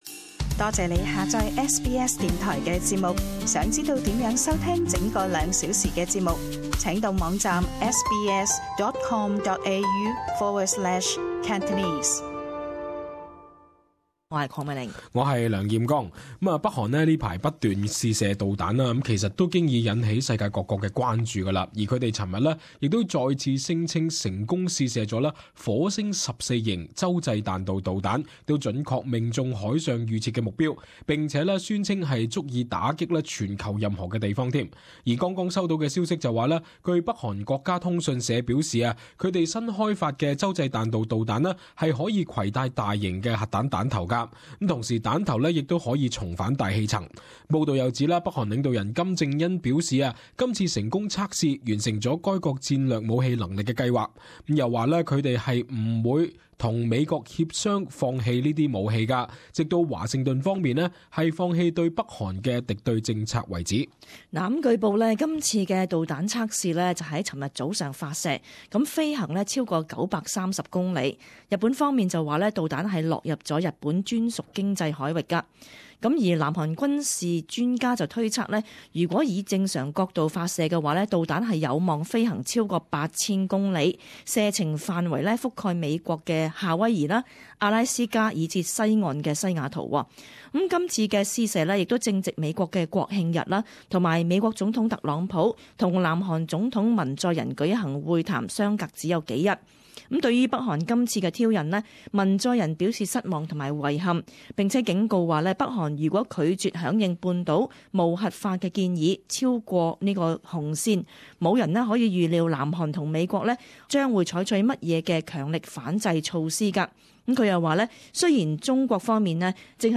【時事報導】各國譴責北韓再次試射彈道導彈